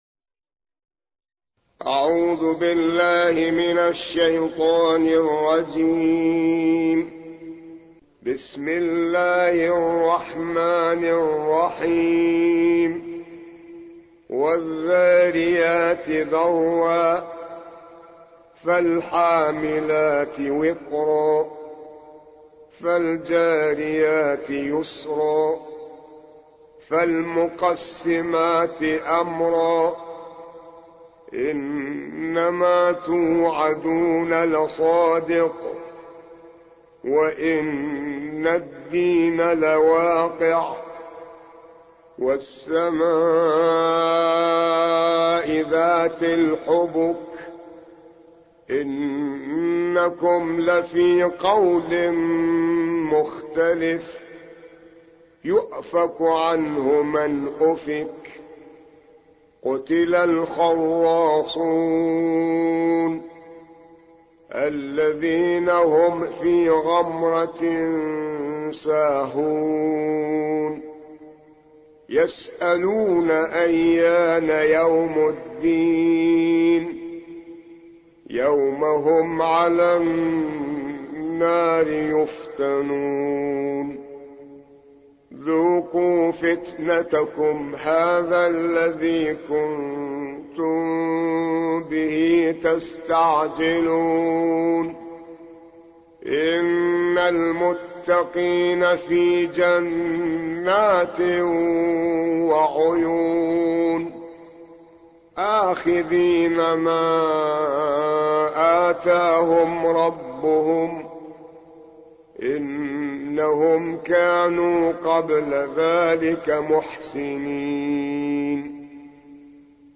51. سورة الذاريات / القارئ